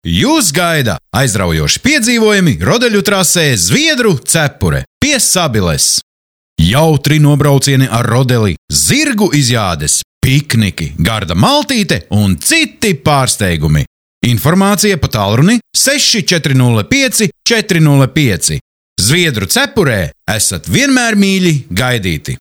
Professioneller lettischer Sprecher für TV / Rundfunk / Industrie.
Sprechprobe: Industrie (Muttersprache):
Professionell voice over artist from Latvia.